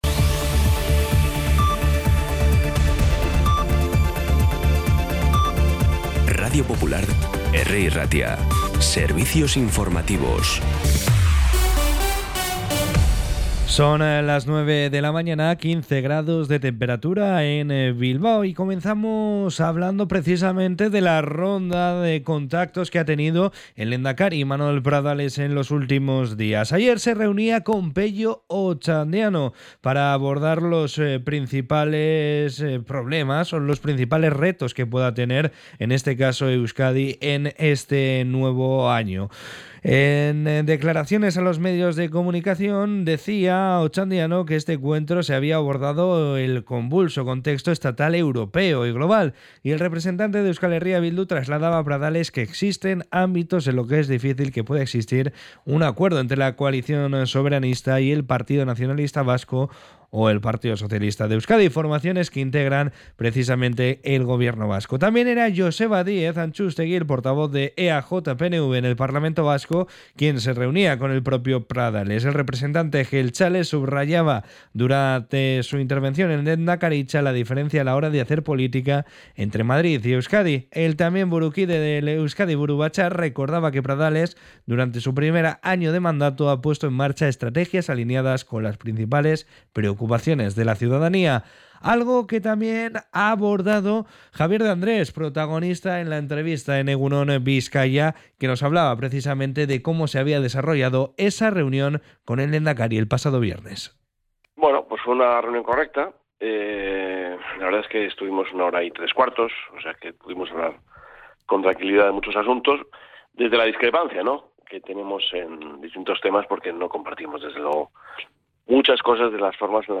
Las noticias de Bilbao y Bizkaia del 10 de septiembre a las 9
Los titulares actualizados con las voces del día. Bilbao, Bizkaia, comarcas, política, sociedad, cultura, sucesos, información de servicio público.